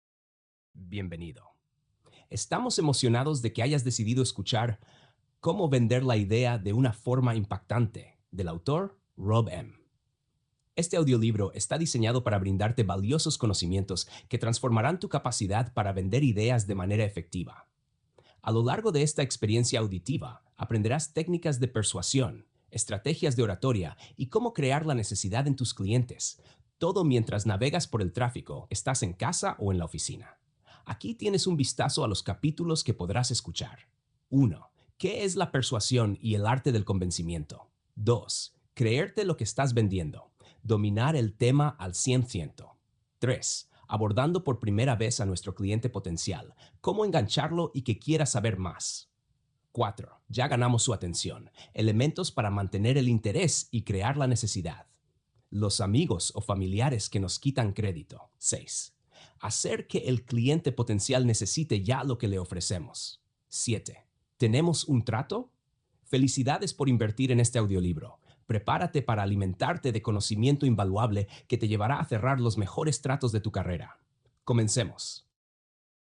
Intro del Audio Libro: